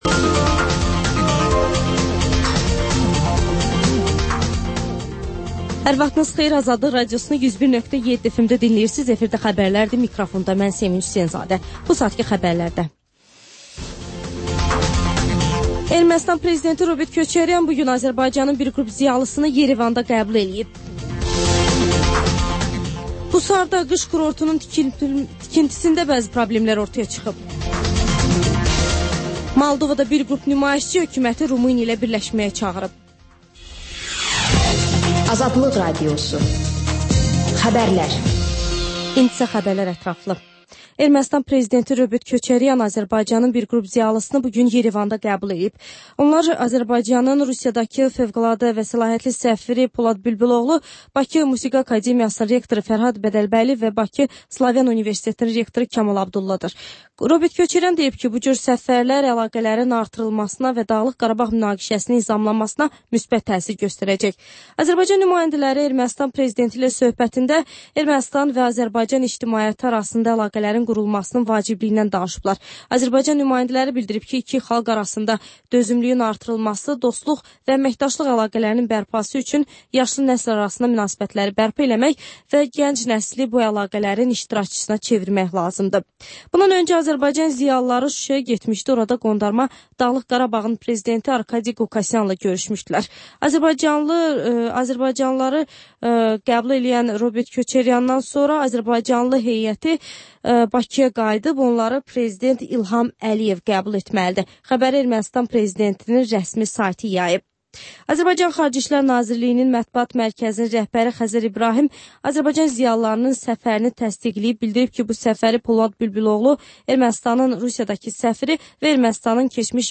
Xəbərlər, müsahibələr, hadisələrin müzakirəsi, təhlillər, sonda TANINMIŞLAR verilişi: Ölkənin tanınmış simalarıyla söhbət